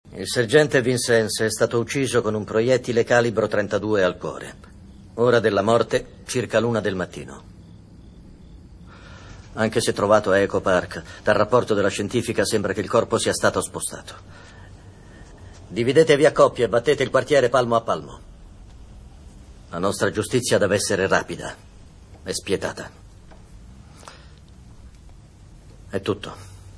nei film "Ghostbusters", in cui doppia Dan Aykroyd, e "HATES - House at the End of the Street", in cui doppia Gil Bellows.